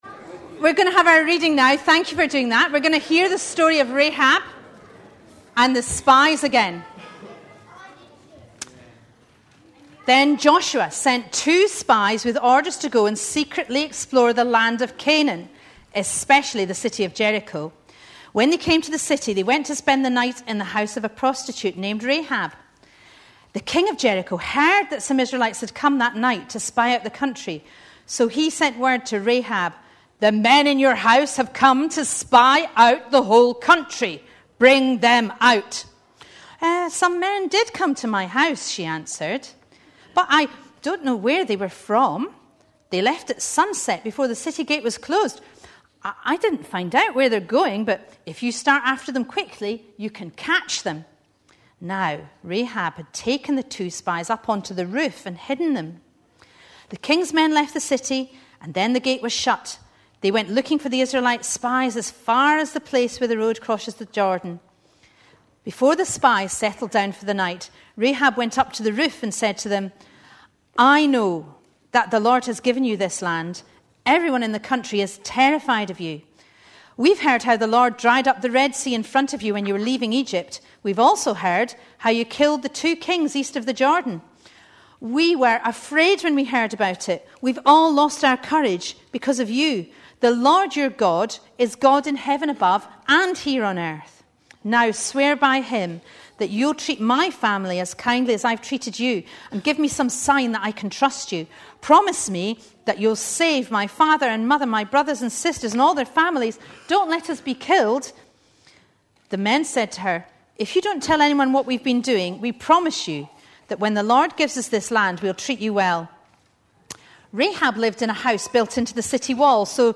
A sermon preached on 17th October, 2010.
etc. The talk featured a clip from the `Johnny English' film, not included here.